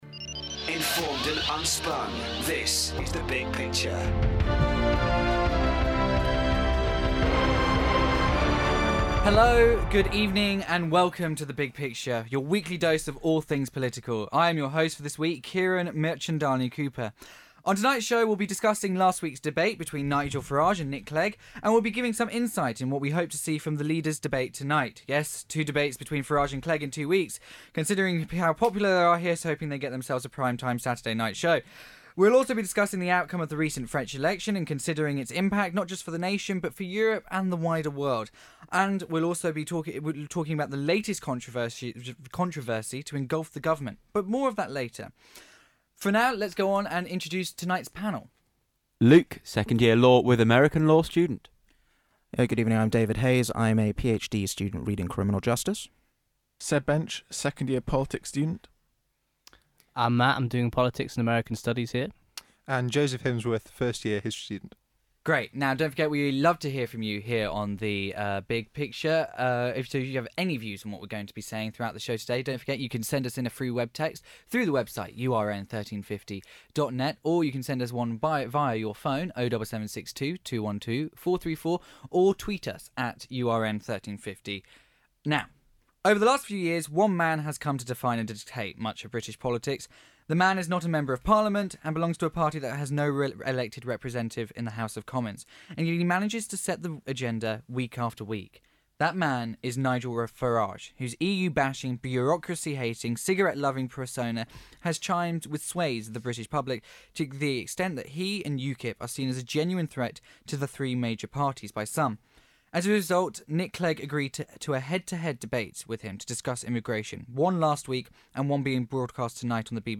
The panel discussed the debates between Nick Clegg and Nigel Farage over the European Union, the recent heavy losses for the governing Socialists in the recent French elections, and the "Books for Prisoners" scandal engulfing the Ministry of Justice.